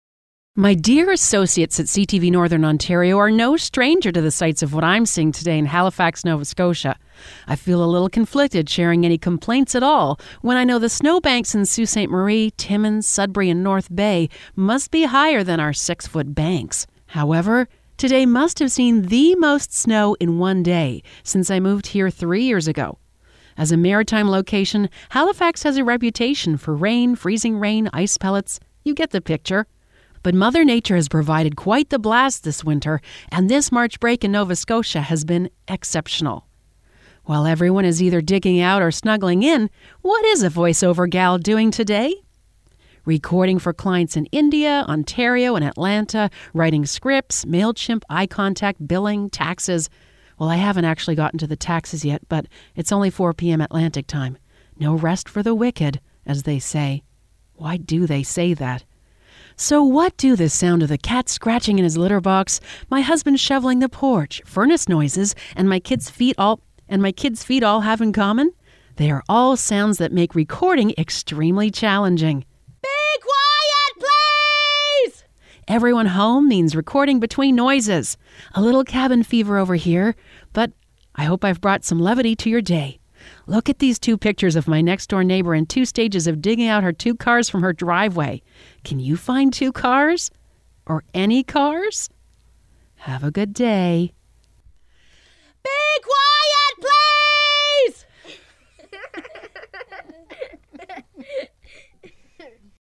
Cat Scratch Fever* (audio version of blog below) My dear associates at CTV Northern Ontario are no stranger to the sights of what I’m seeing today in Halifax, Nova Scotia.